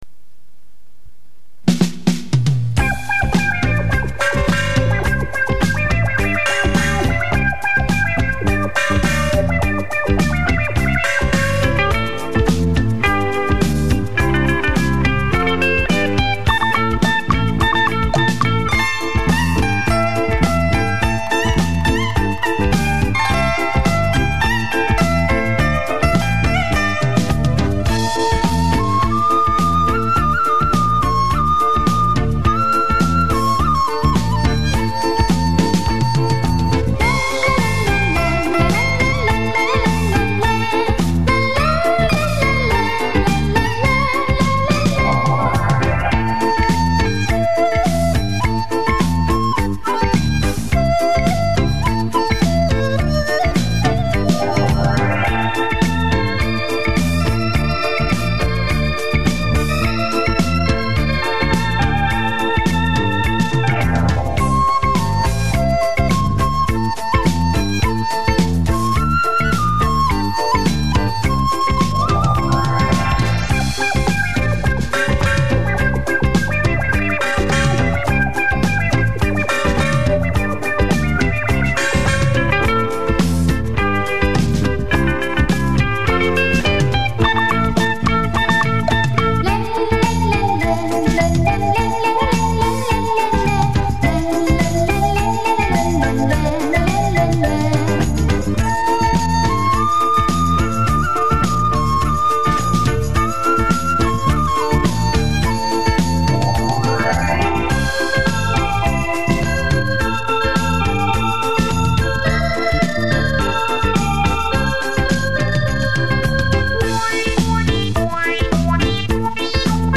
专辑分类：纯音乐